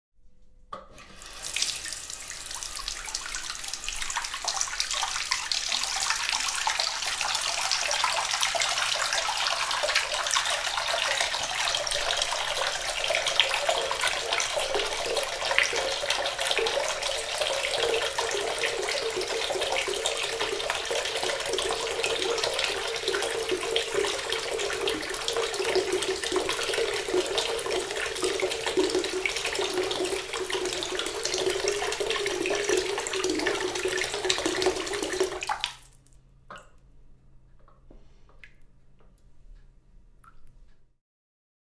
用AT4021录制到改装的Marantz PMD661中。
Tag: 泡沫 填充 汩汩 液体